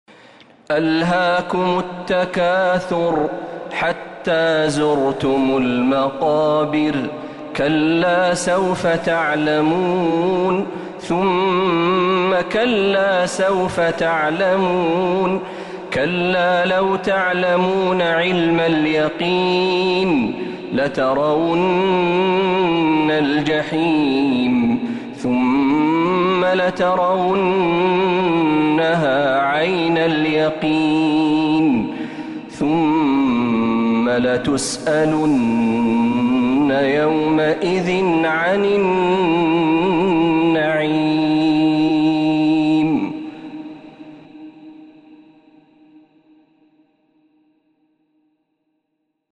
سورة التكاثر كاملة من الحرم النبوي